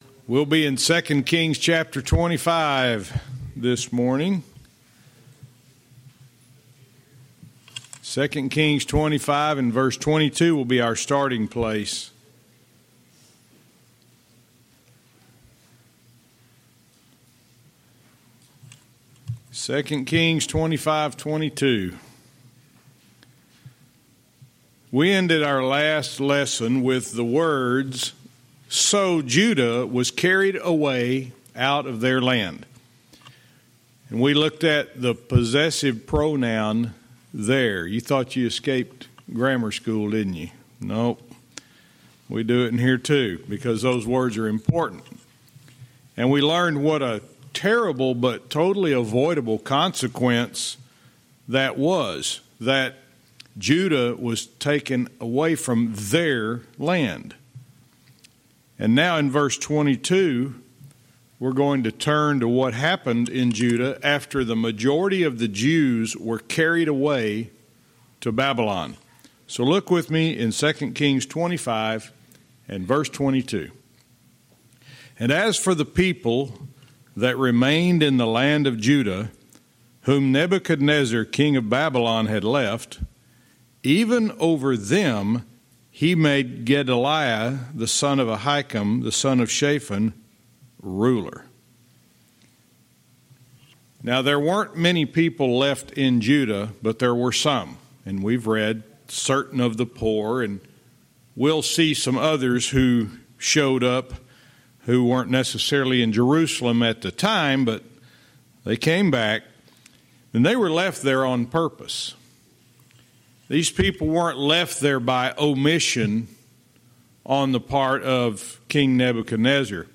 Verse by verse teaching - 2 Kings 25:22-30